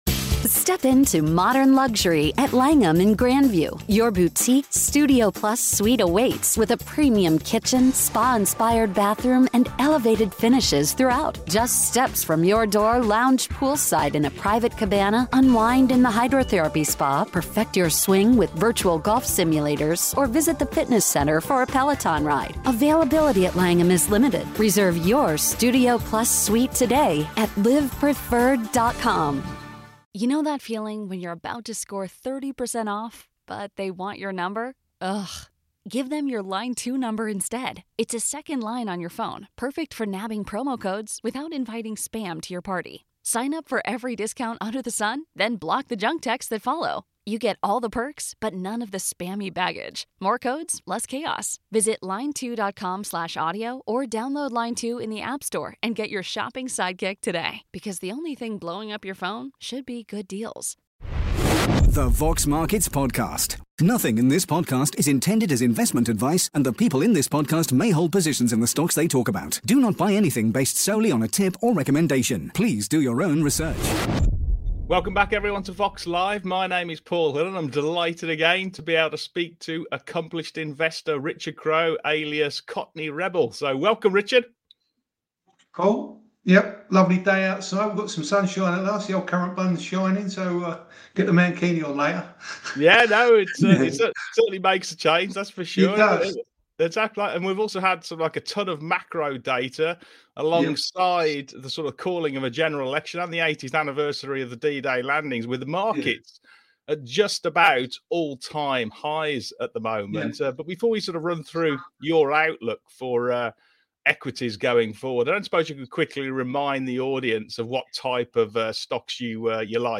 Live on today's Vox Markets Exchange